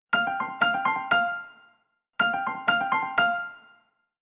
call_ringtone3.wav